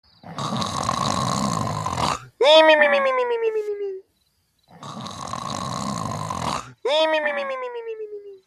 Homer Snoring Sound Effect - Bouton d'effet sonore